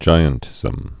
(jīən-tĭzəm)